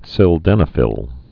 (sĭl-dĕnə-fĭl)